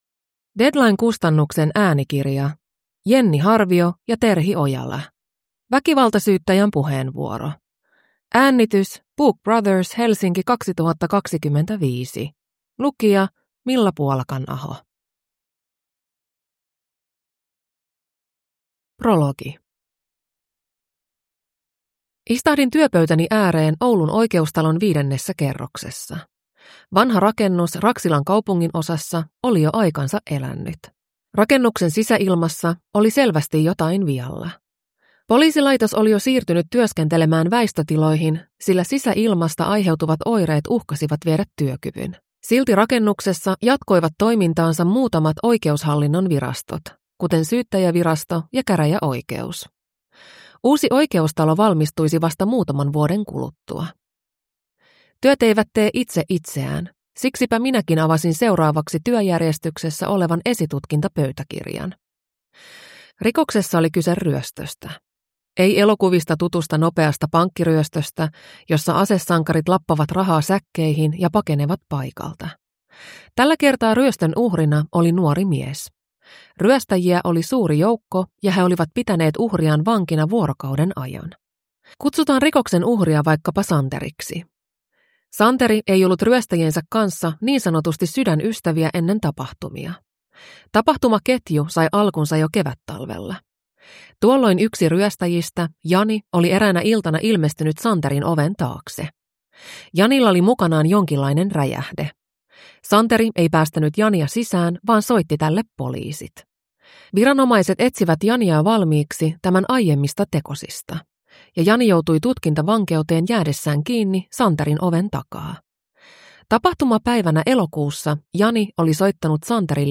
Väkivaltasyyttäjän puheenvuoro (ljudbok